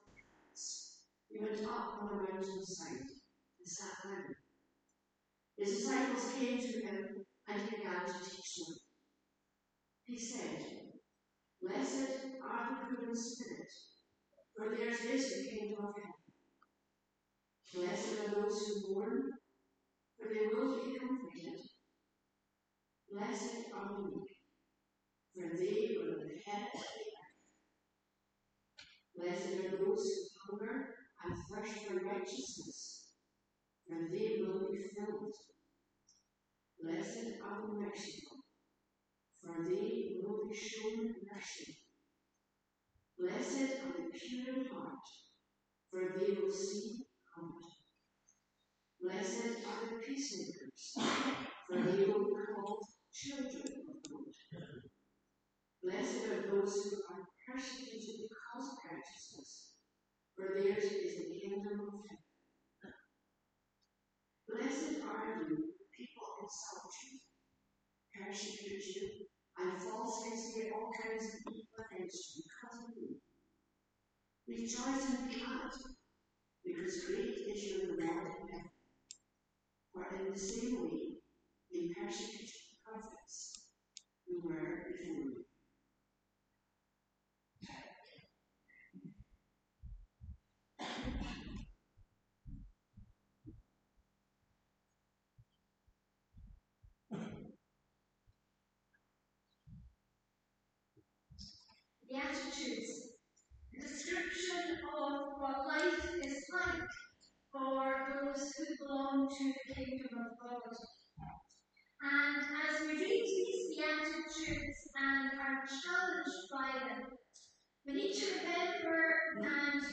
Passage: Matthew 5: 1-12 Service Type: Sunday Morning